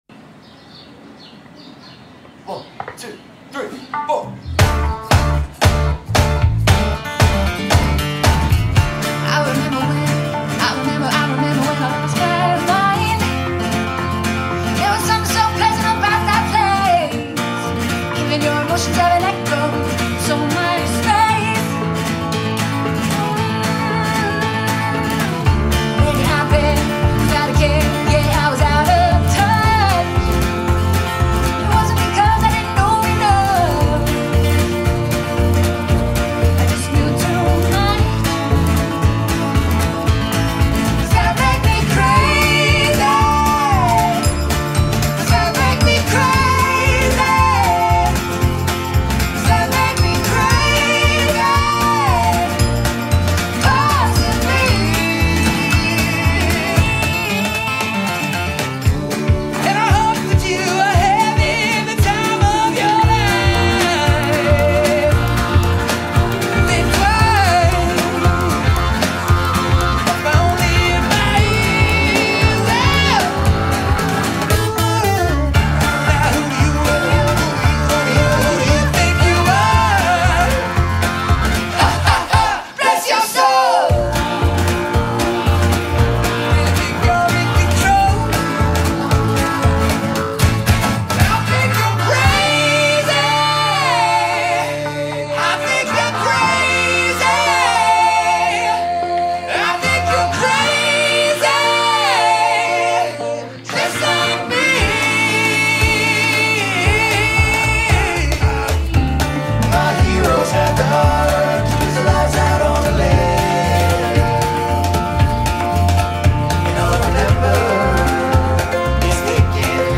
In the verse "Maybe I'm crazy" - you can hear little bells being played....